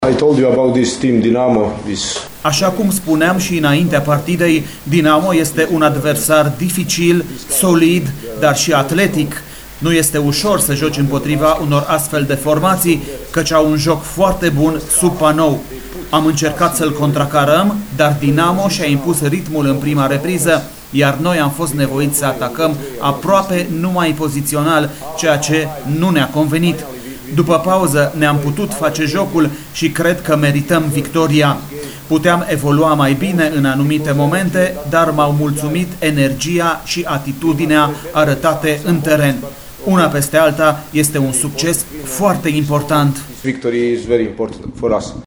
după victoria din această seară: